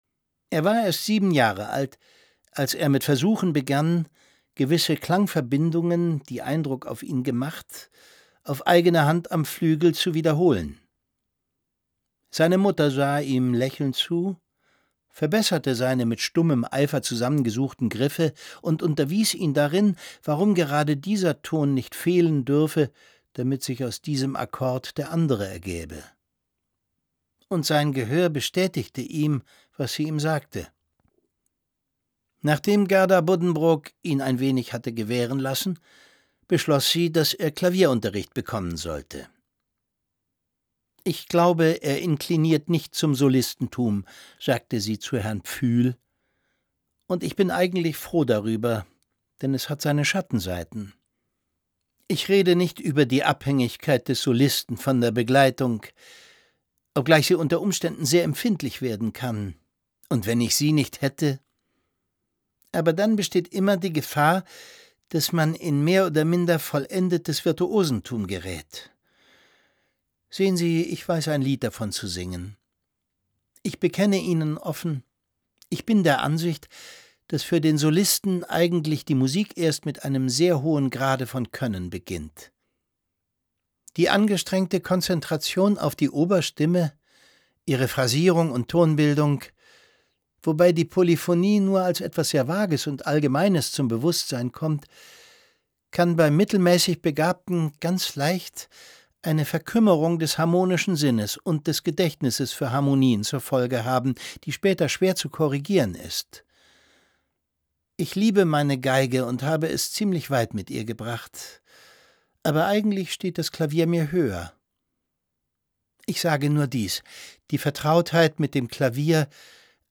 Der kleine Hanno lauscht stundenlang fasziniert, wenn seine Mutter und Herr Pfühl musizieren. Es liest Thomas Sarbacher.